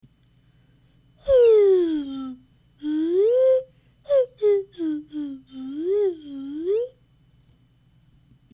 スライドホイッスル写真 スライドホイッスル(ペットボトル)　＜1999年＞
５００ｍｌの飲料のペットボトルでもできますが、音はラムネビンの方が格段に出しやすい。